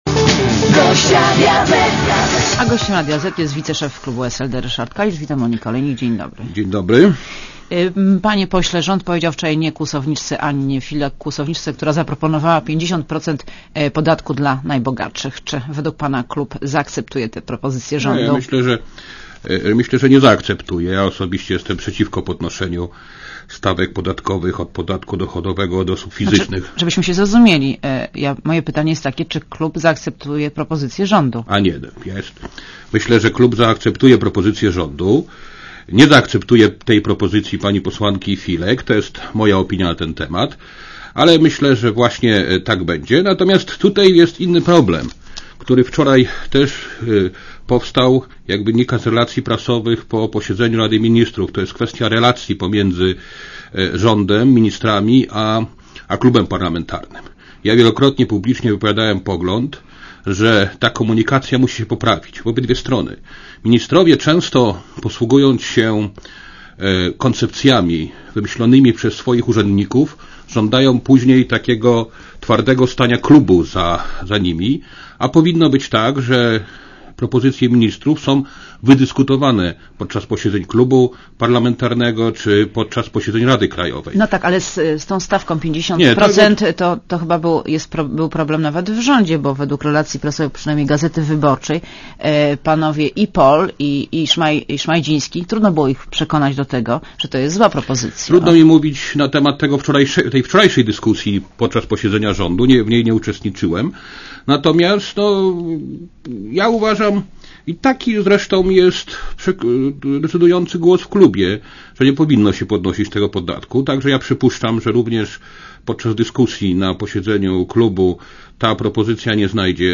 Posłuchaj wywiadu (2.7 MB) Gościem Radia Zet jest wiceszef klubu SLD Ryszard Kalisz .